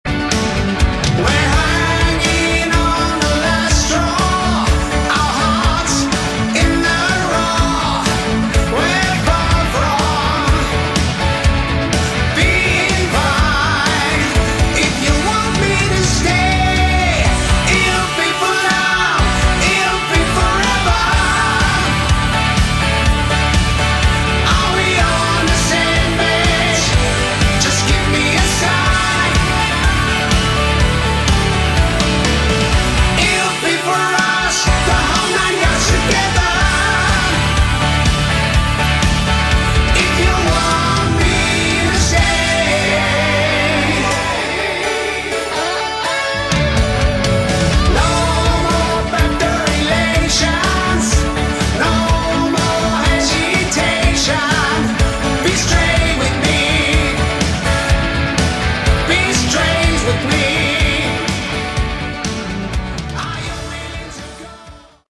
Category: Hard Rock
lead and backing vocals
guitars, keys, backing vocals
drums
bass